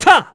Esker-Vox_Attack4_kr.wav